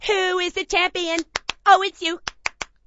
gutterball-3/Gutterball 3/Commentators/Poogie/who_champ_its_you.wav at 74d596934f75f36535b154cb0fe3e793fb94b25b
who_champ_its_you.wav